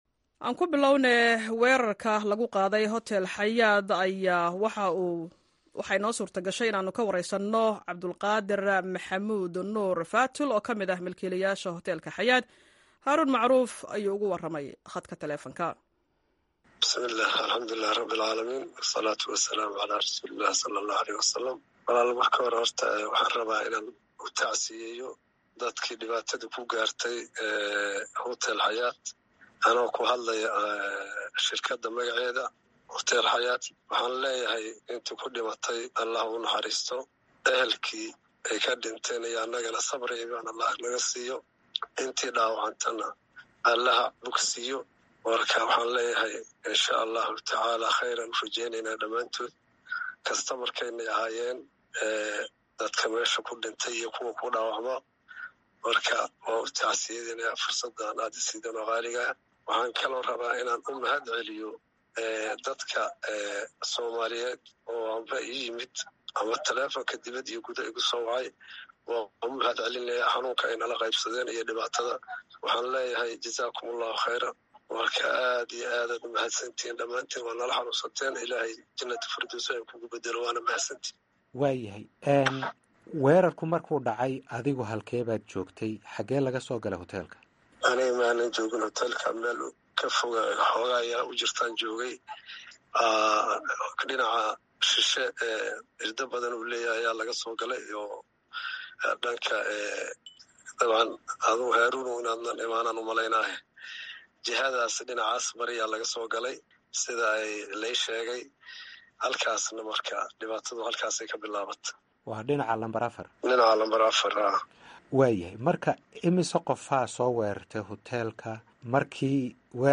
la hadlay idaacada VOA-da